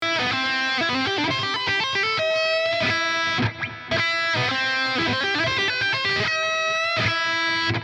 Ich hätte da noch ein Lick für Euch ...
Würde dann - Achtung synthetischer Garageband Drummer - so klingen ...